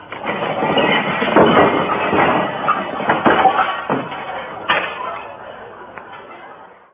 Sound of Fibber's closet opening
closet.wav